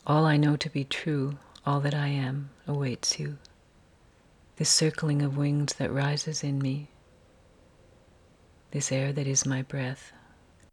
Source: Retrograde text